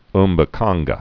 (mbə-känggə)